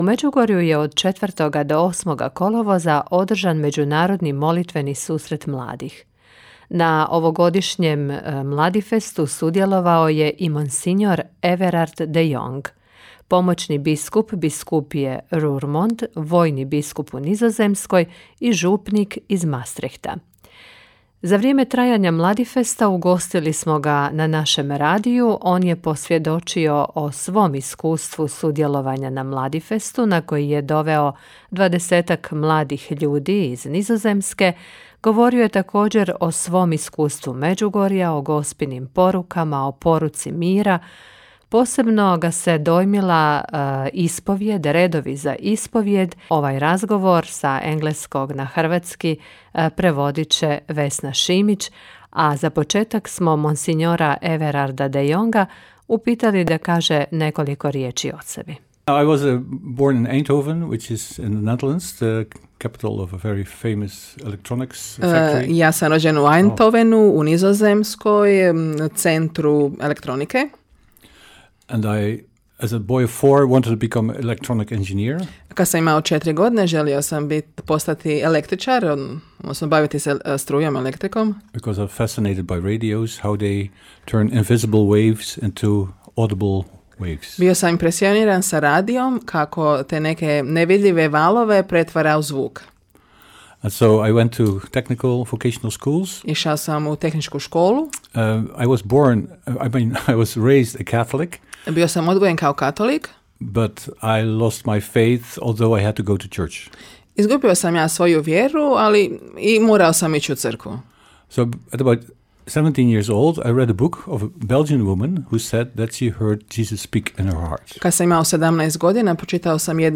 Gostujući u programu našega radija govorio je o svom iskustvu Međugorja, o Gospinim porukama, te o iskustvu Mladifesta tijekom kojega je svaku večer bio na svetoj misi u koncelebraciji s brojnim svećenicima.